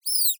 sfx_bunny_squeak_v3.ogg